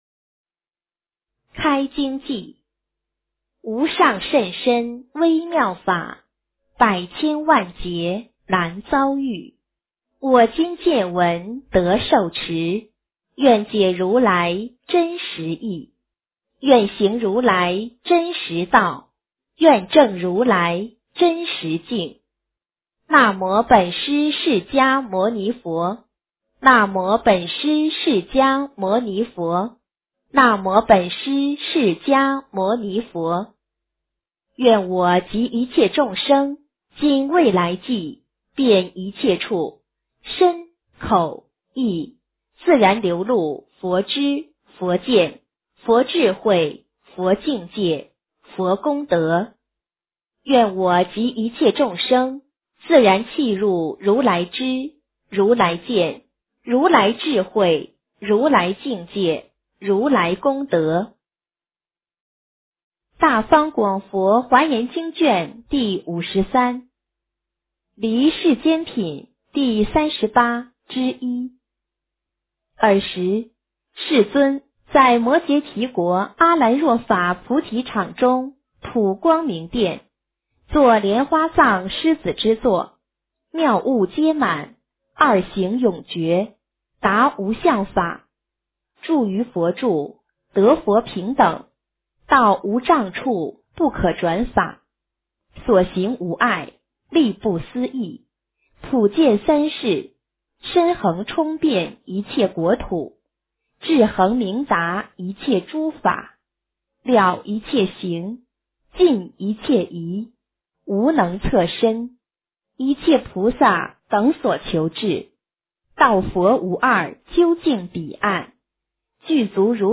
华严经53 - 诵经 - 云佛论坛